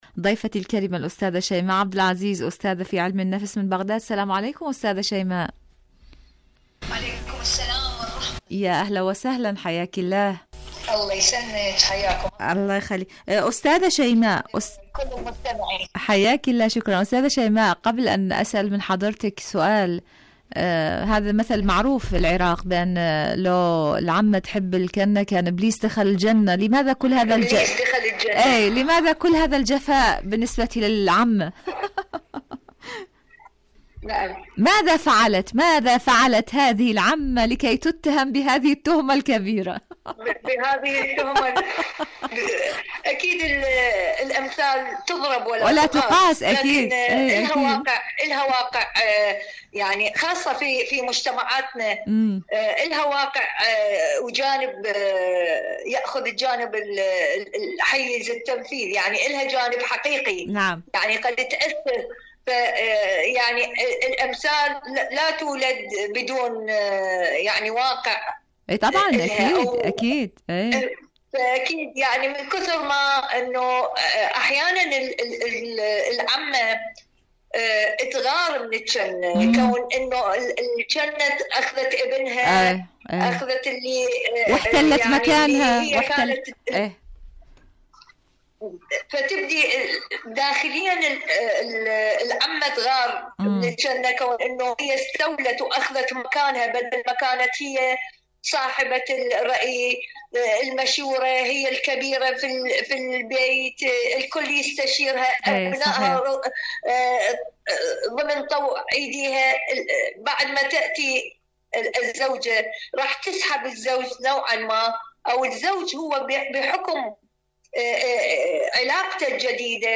مقابلات برامج إذاعة طهران العربية برنامج عالم المرأة المرأة مقابلات إذاعية أحبك يا حماتي بحبك يا حماتي احب اقولك يا حماتي حماتي يا حماتي شاركوا هذا الخبر مع أصدقائكم ذات صلة زوجي معنف للأطفال ماذا أفعل؟..